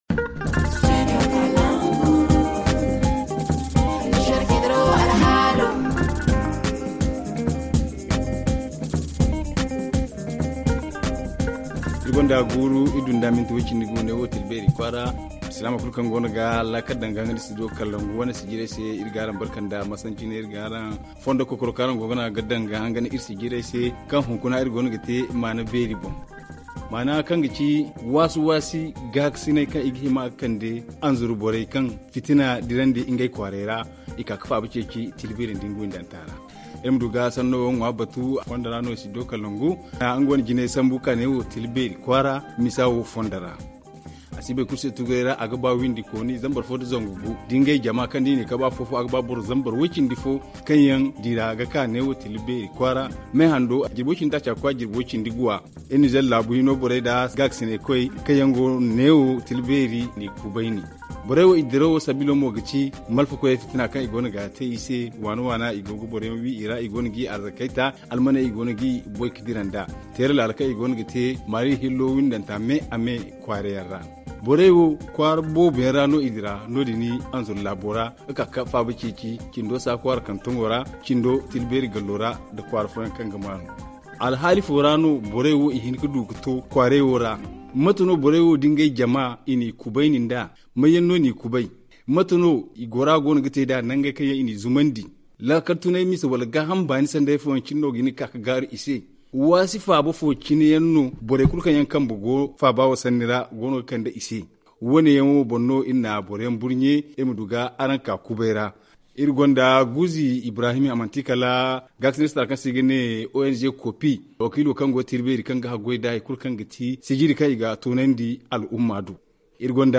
Suite et fin de nos forums réalisés à Tillabéry avec les déplacés d’Anzourou.